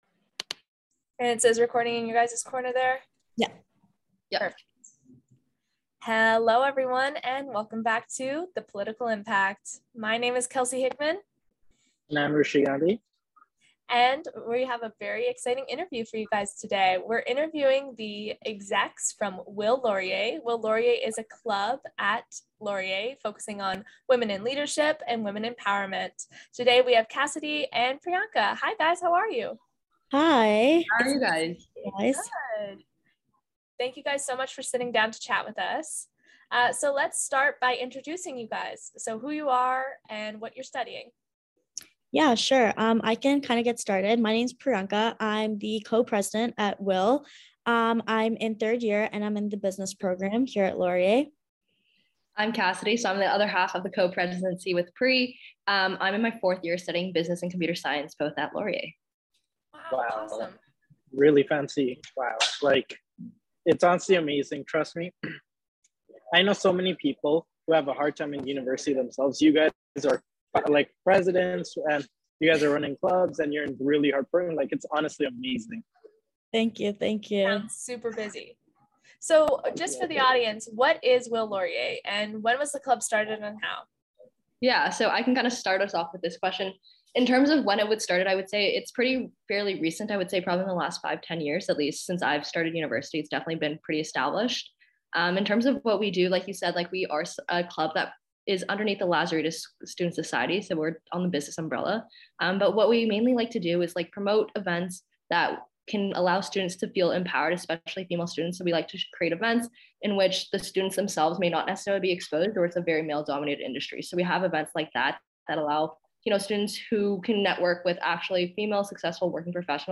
The Political Impact Ep 12 - Breaking the Glass Ceiling: Interview with Willaurier